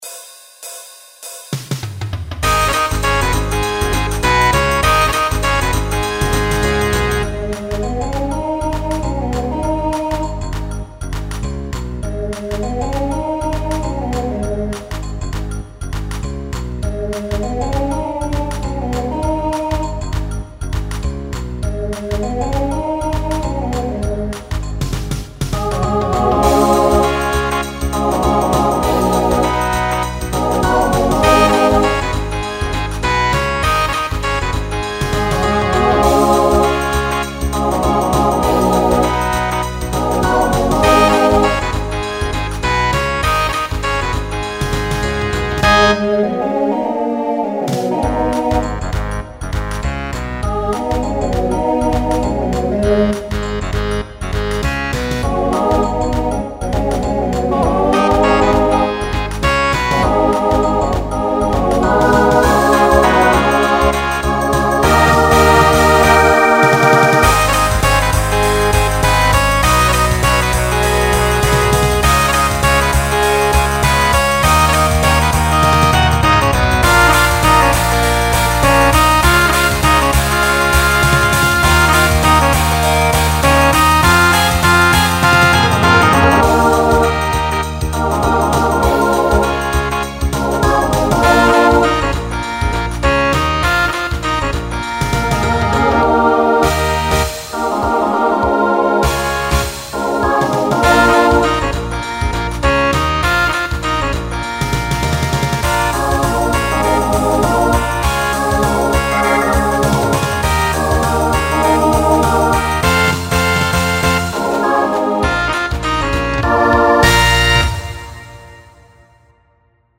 Rock , Swing/Jazz Instrumental combo
Voicing SATB